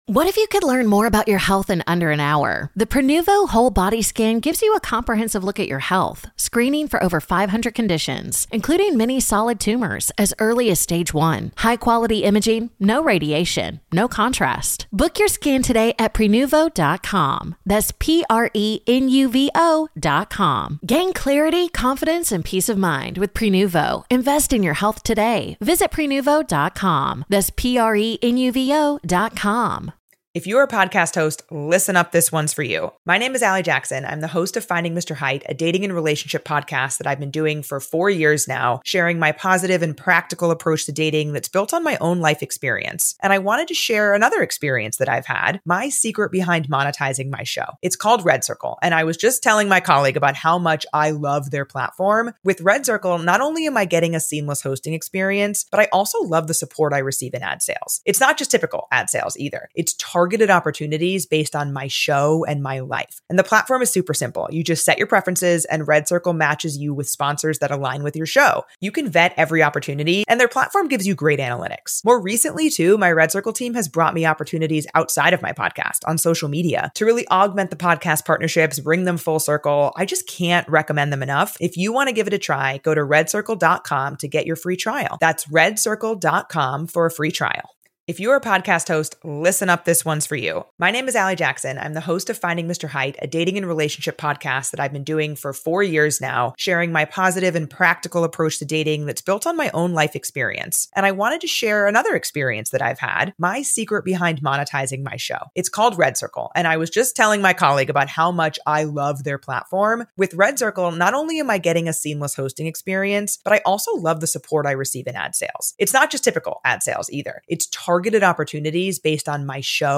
A true crime podcast, hosted by a college professor and administrator, focusing on crimes committed on school campuses or crimes that are somehow associated with colleges and universities.